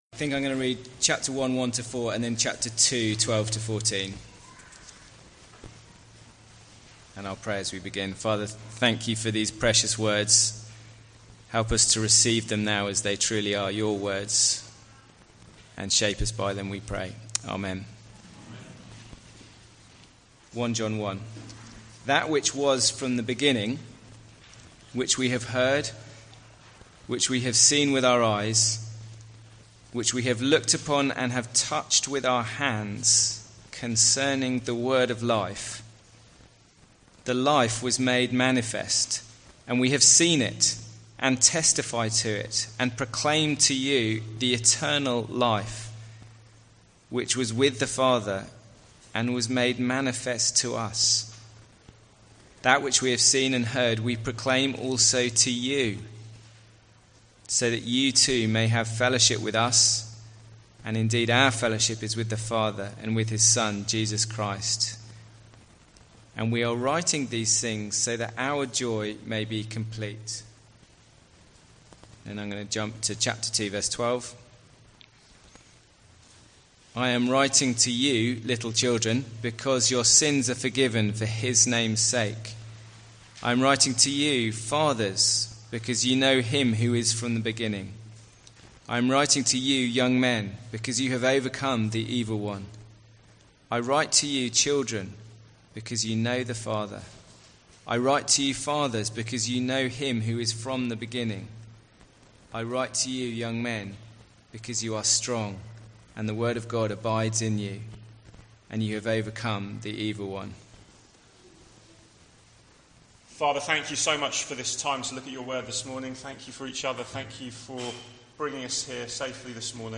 Series: Weekend Away July 2021 | 1 John